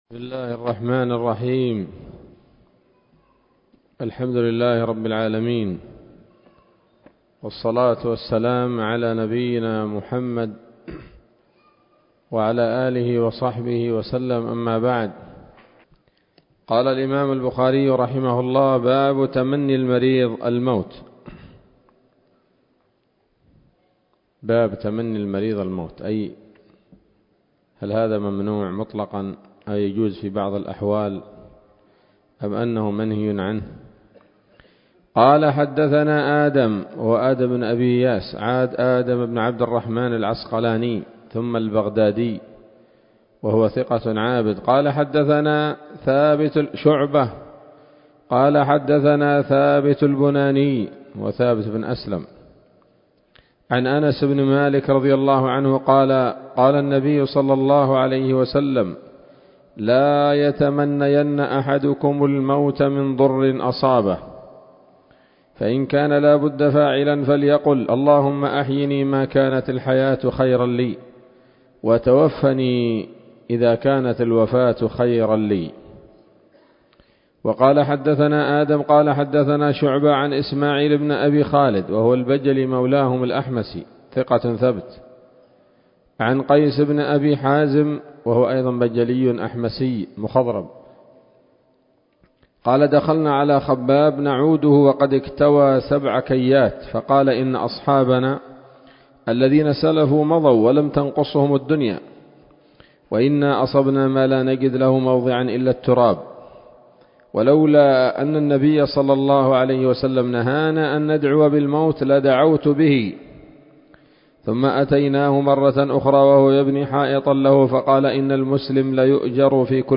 الدرس الخامس عشر من كتاب المرضى من صحيح الإمام البخاري